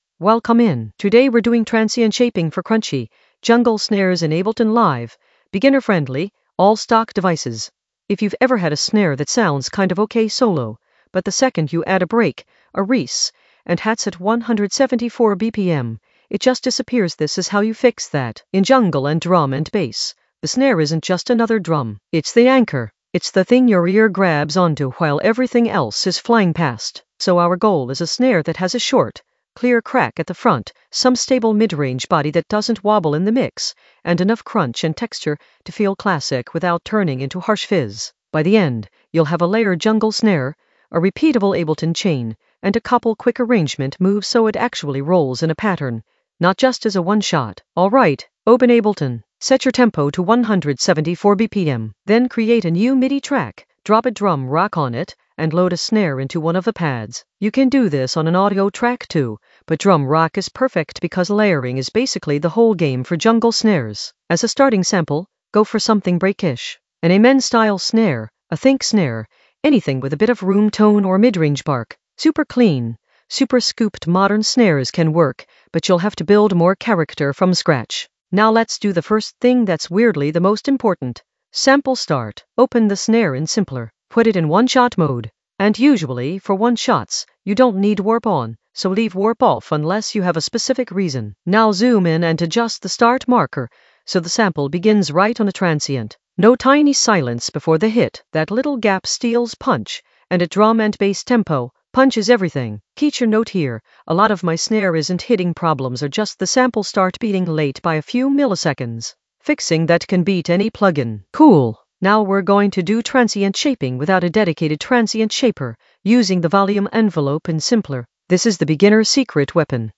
Narrated lesson audio
The voice track includes the tutorial plus extra teacher commentary.
An AI-generated beginner Ableton lesson focused on Transient shaping for crunchy jungle snares in the Drums area of drum and bass production.